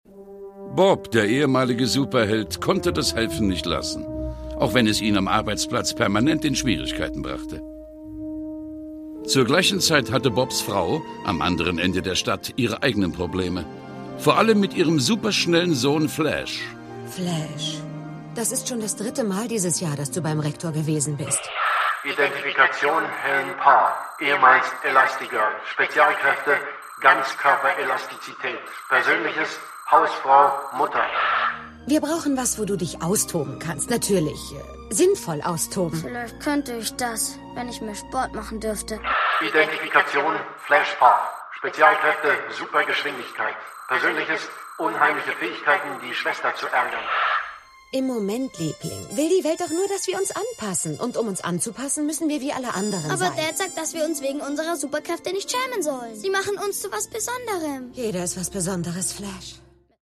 Das Original-Hörspiel zum Disney/Pixar Film
Produkttyp: Hörspiel-Download